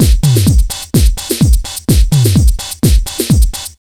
127BEAT8 4-R.wav